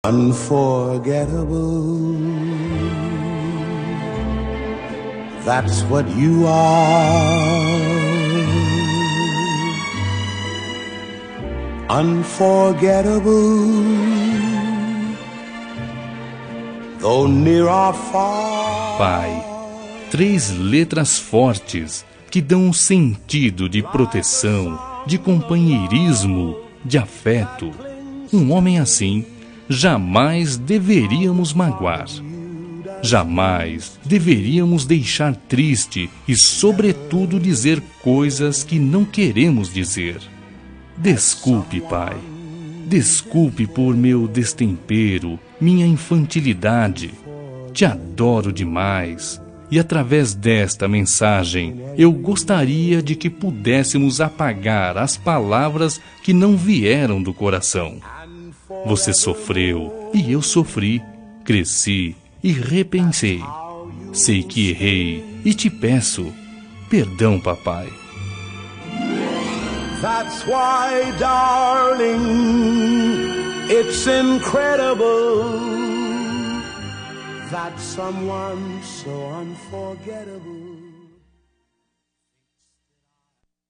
Reconciliação Familiar – Voz Masculina – Cód: 088736 – Pai